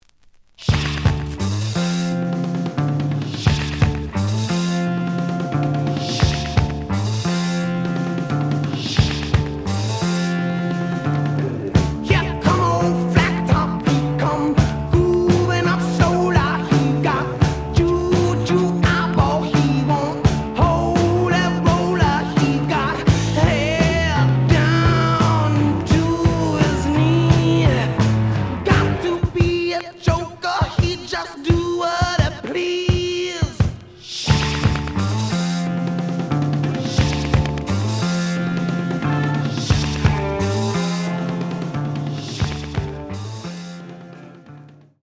試聴は実際のレコードから録音しています。
●Genre: Rock / Pop
●Record Grading: VG~VG+ (両面のラベルにダメージ。盤に若干の歪み。プレイOK。)